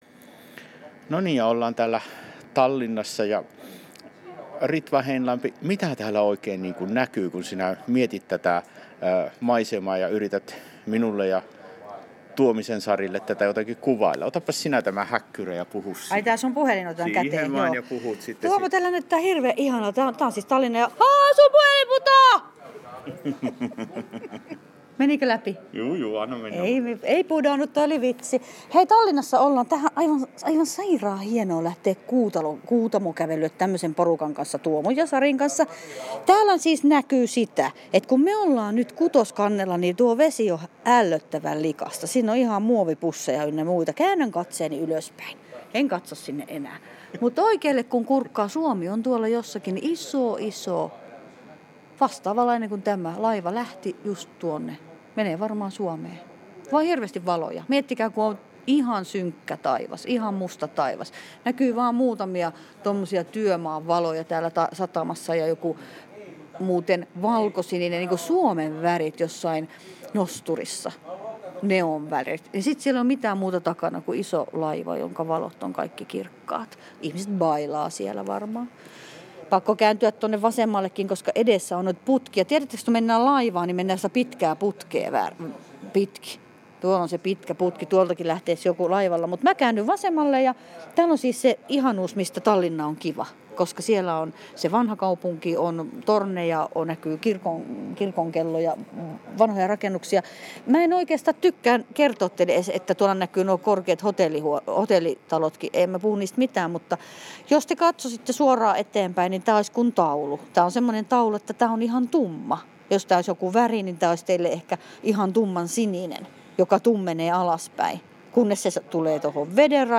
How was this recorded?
kuvailee M/S Baltic queen laivalta Tallinan sataman näkymiä.